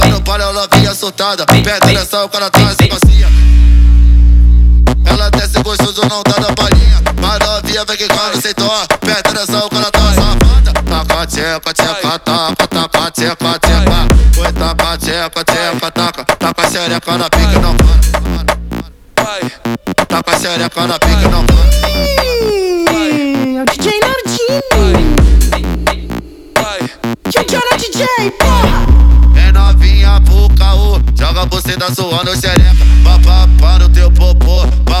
Жанр: Поп / Электроника